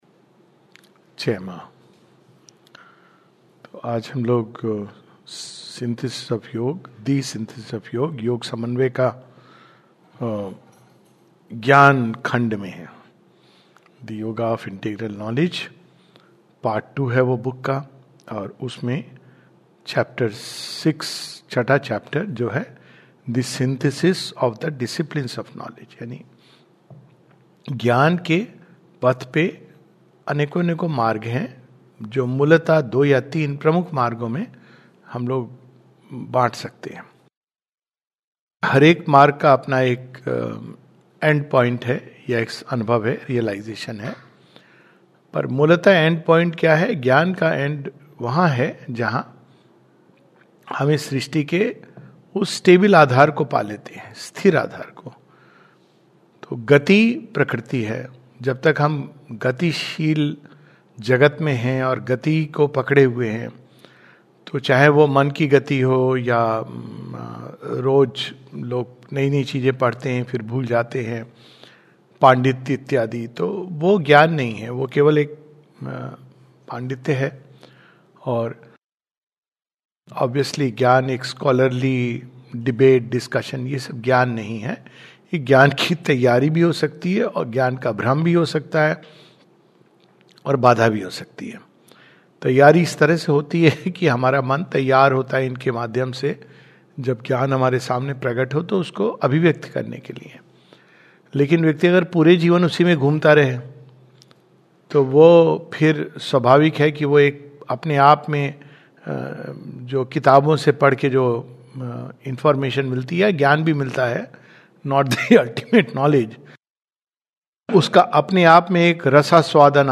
This is a summary of Chapter 6 of Part II of The Synthesis of Yoga. A talk